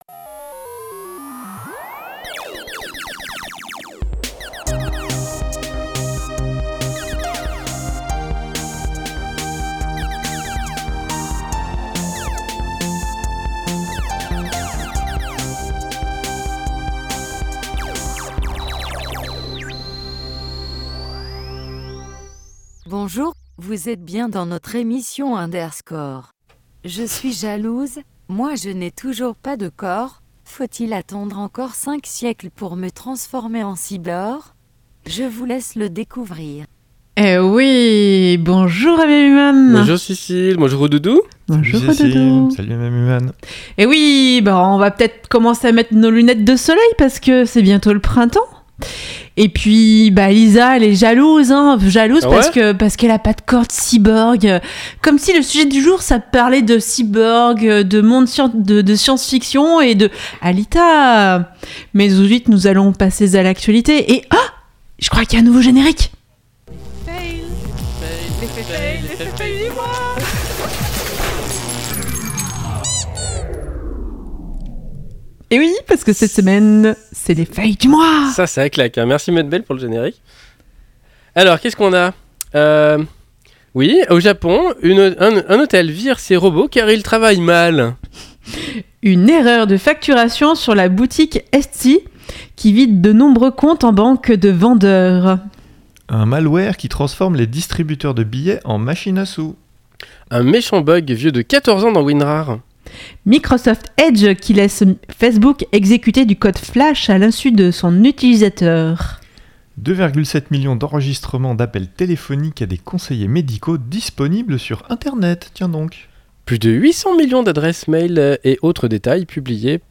Le flim Alita De l'actu, une pause chiptune, un sujet, l'agenda, et astrologeek !